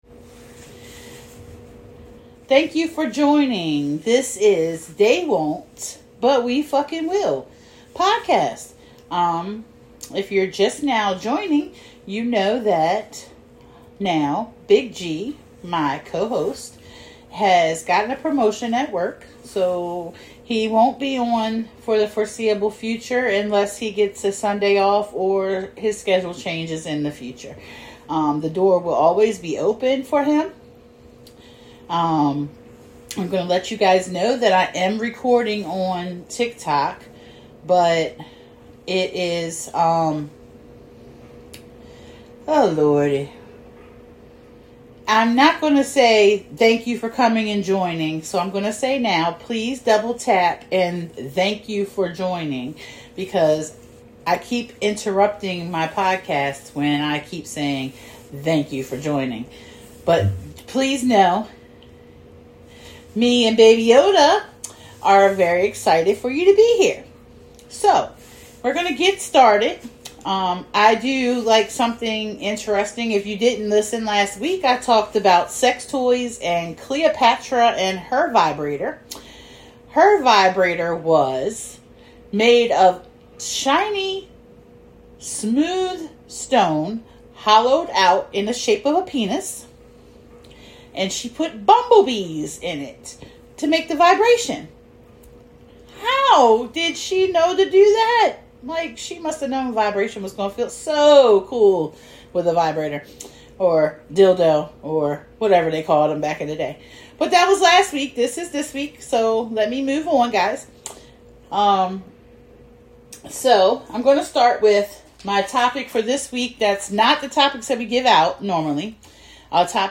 For the time being it should be called They won’t but I f’n will podcast since it is just lil ole me for the moment.